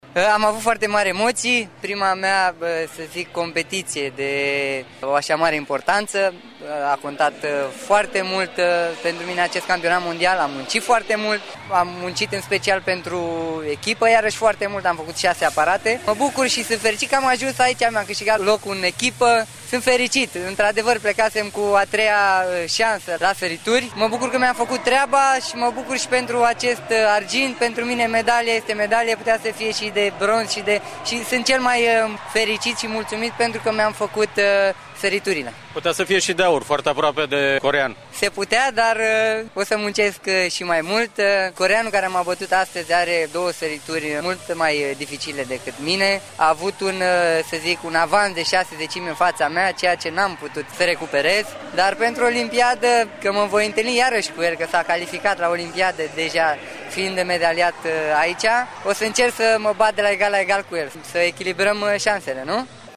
Îl puteţi asculta într-un fragment de interviu